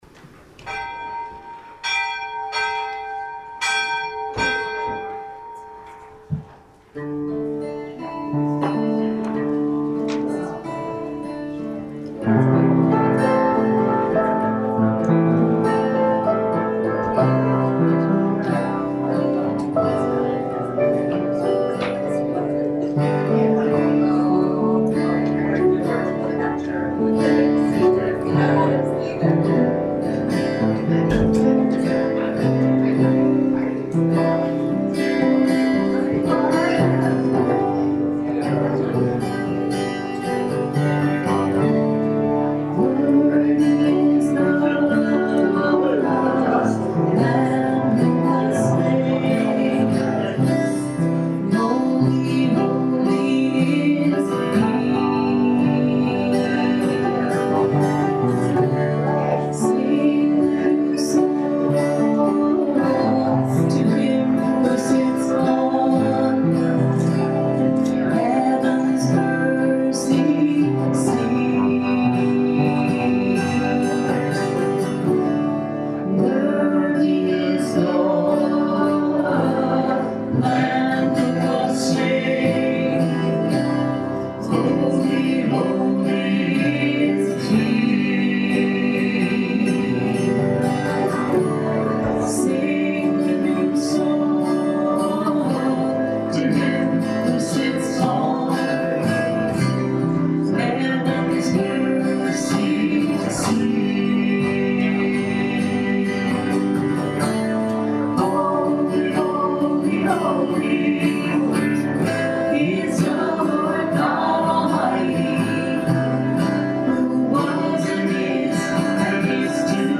April 10th, 2016 Service Podcast